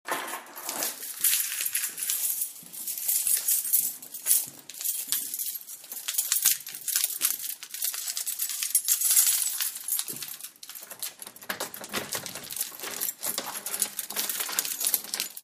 am rhein: zwei scheiben wei�brot (zertreten), zwei stockenten, zwei graug�nse, zwei m�nner, zwei handys, also zwei verbeugungen vor brot.